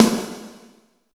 50 VRB SN2-L.wav